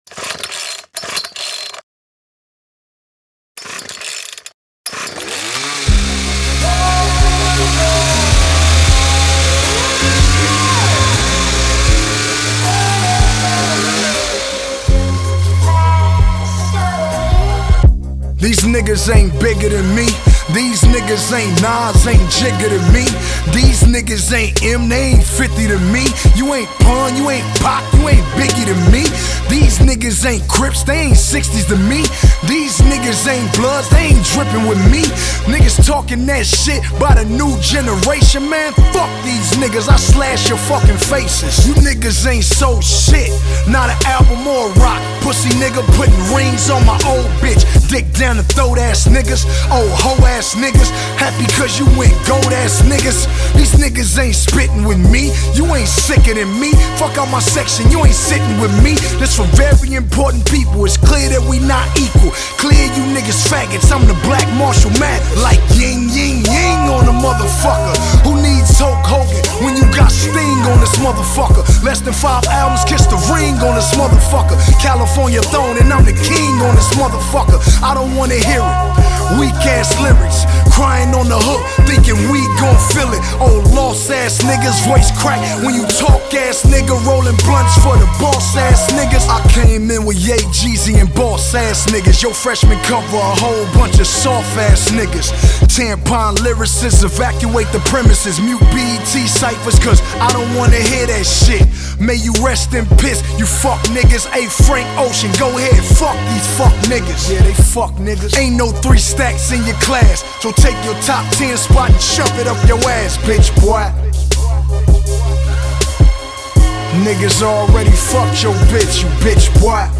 lets off an unrelenting verbal assault